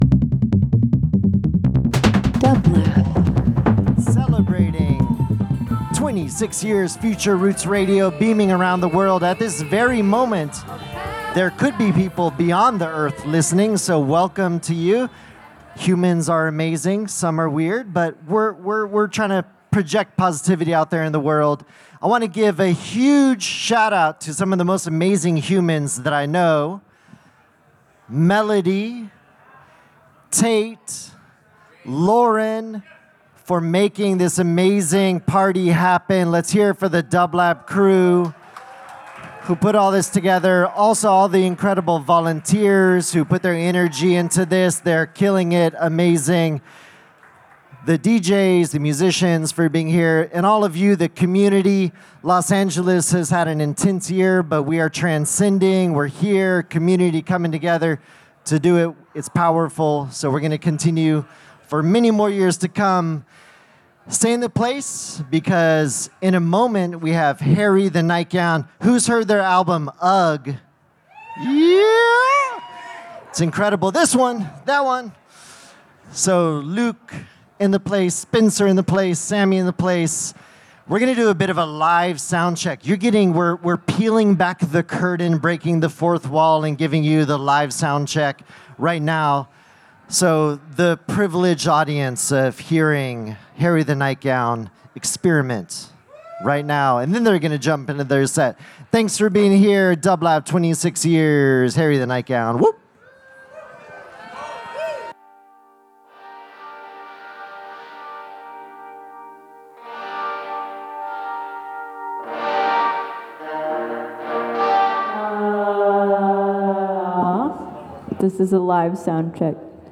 [LIVE FROM THE LOFT @ PORTER STREET STUDIO – OCT 11, 2025]
Avant-Garde Indie Live Performance Pop